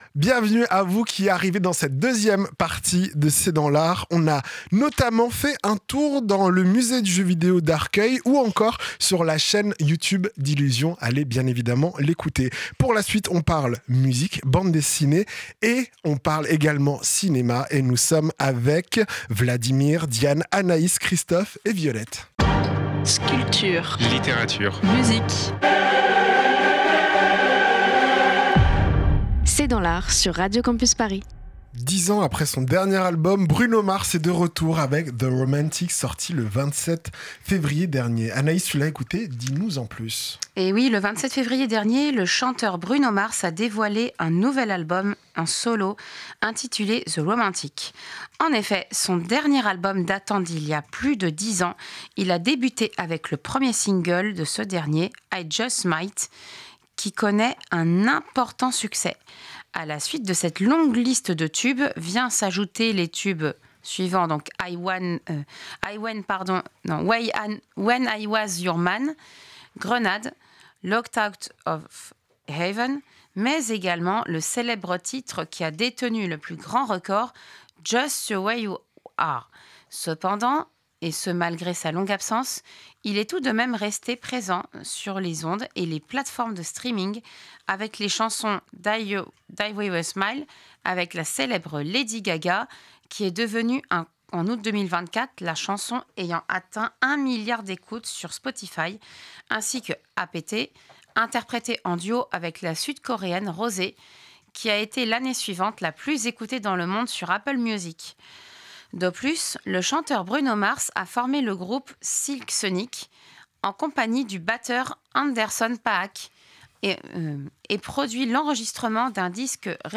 C’est dans l’art, l’émission de recommandation culturelle de Radio Campus Paris.